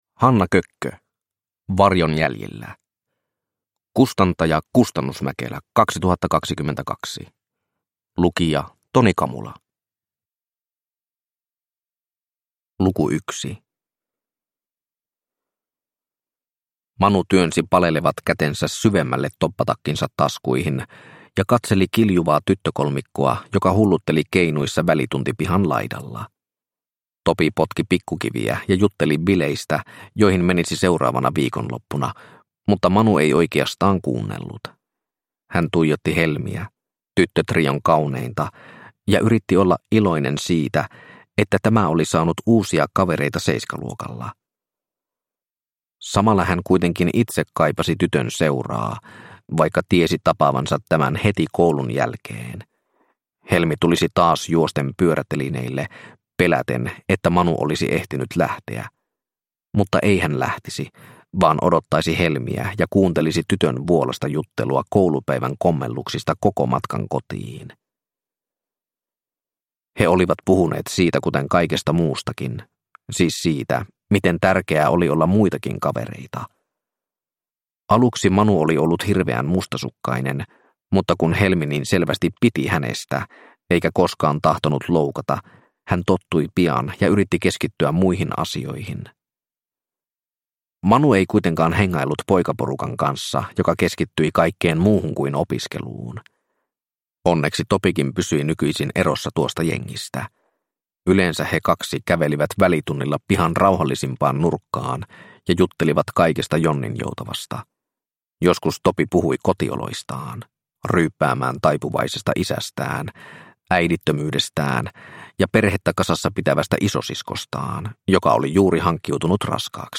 Varjon jäljillä – Ljudbok – Laddas ner